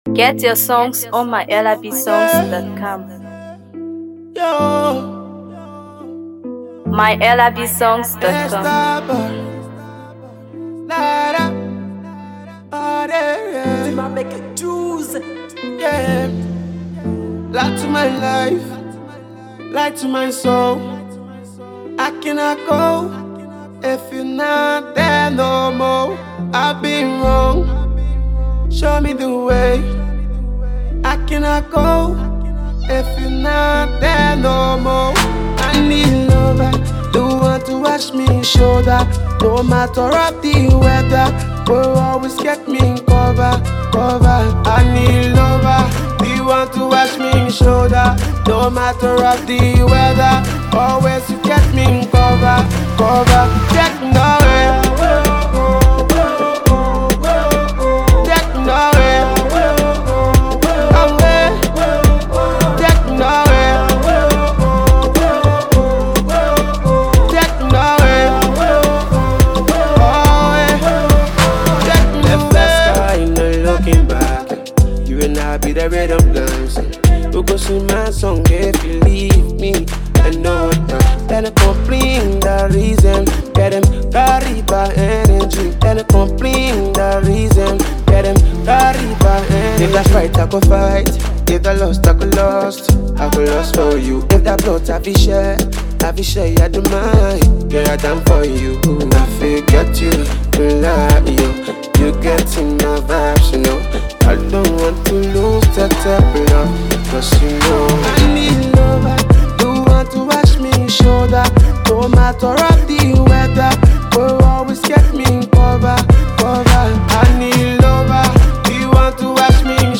Afro PopMusic
With its infectious melodies and heartfelt lyrics